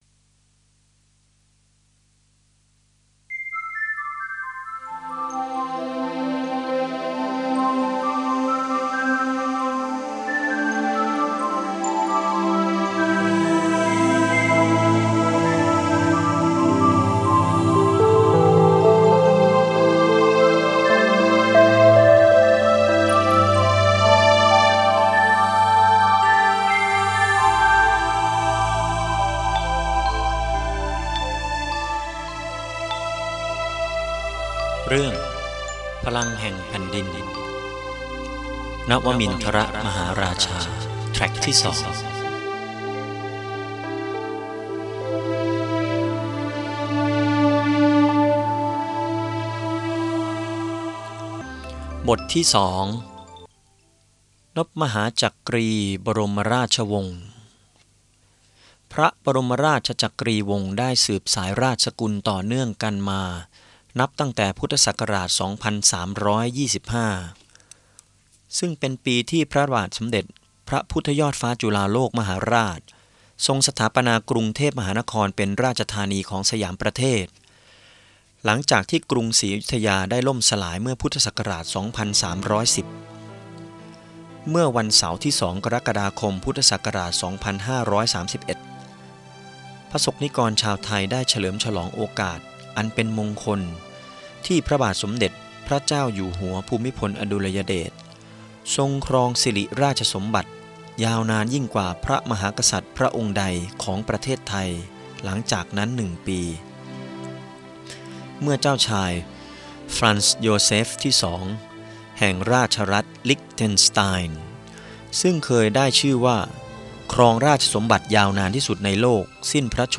หนังสือเสียง จากหนังสือ พลังแห่งแผ่นดิน นวมินทรมหาราชา ตอนที่ 2 นบมหาจักรีบรมราชวงศ์